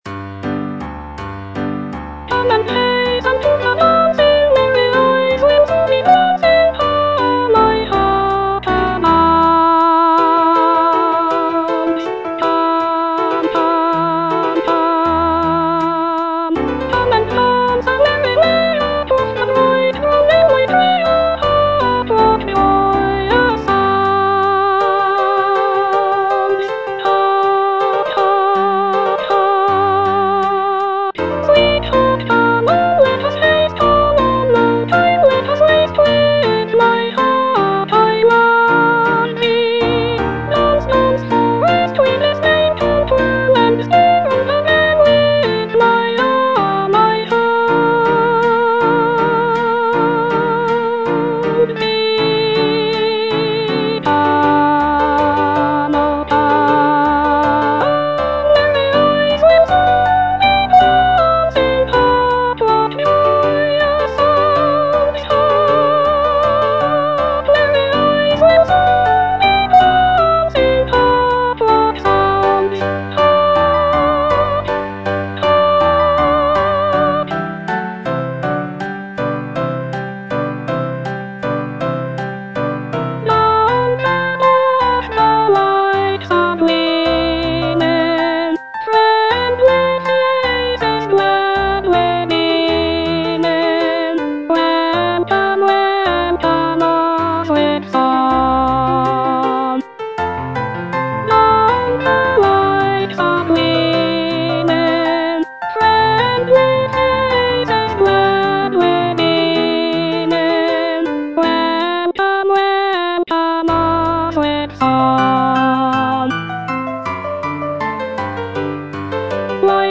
E. ELGAR - FROM THE BAVARIAN HIGHLANDS The dance (soprano II) (Voice with metronome) Ads stop: auto-stop